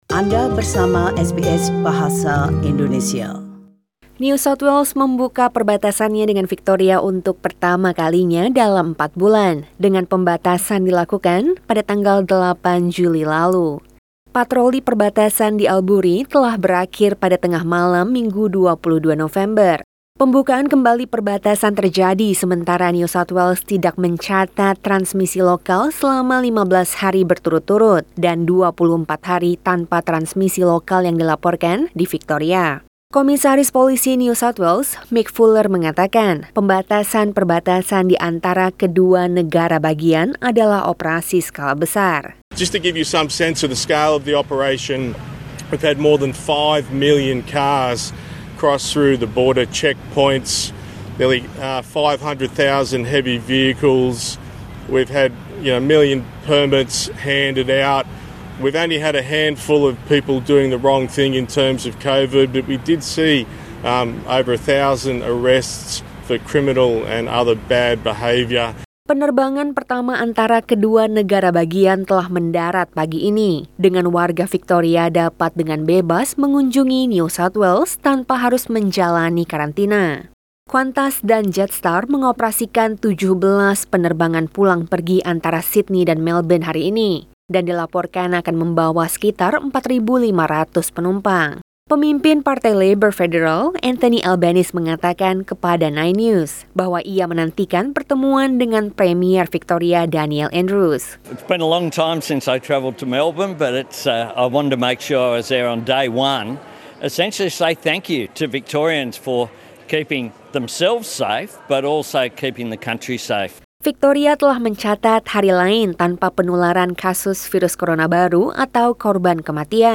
SBS Radio News in Bahasa Indonesia - 23 November 2020
Warta Berita SBS Radio dalam Bahasa Indonesia Source: SBS